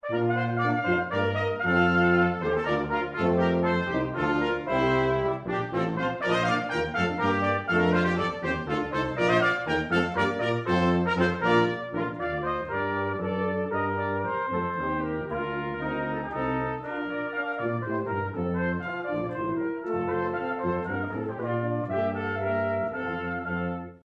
Century Brass is a brass quintet and brass band from St. Paul, Minnesota, USA.